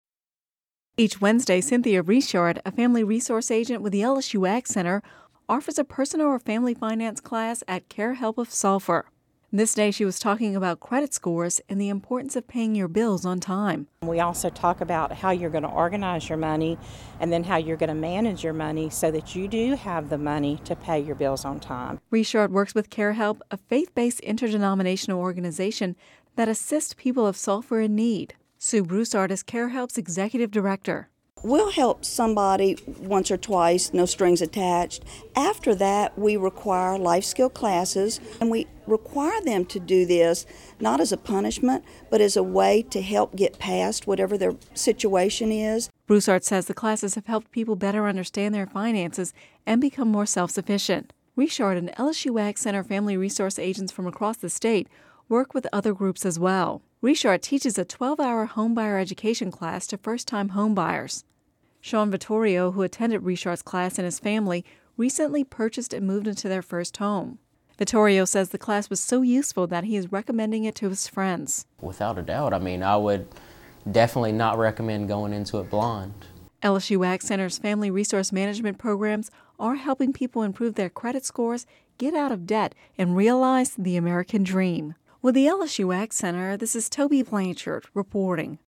(Radio News 04/19/11)